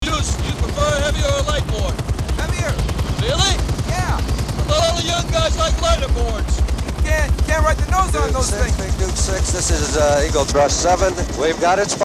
Here's the exact same moment in the audio in these three synthesized channels:
-First, the Center Channel -